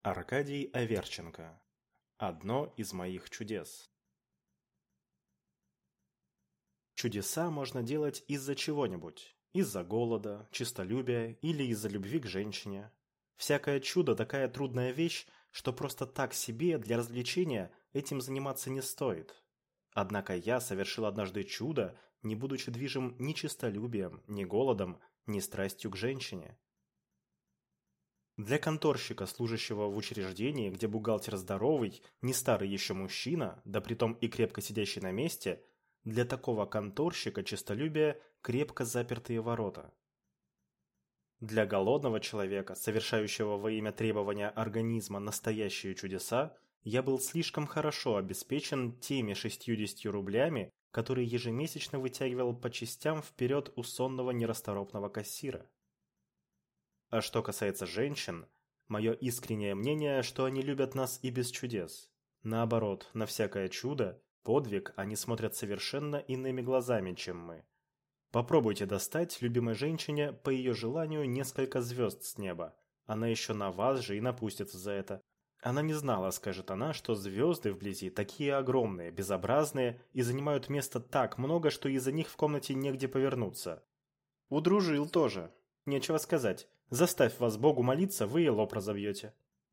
Аудиокнига Одно из моих чудес | Библиотека аудиокниг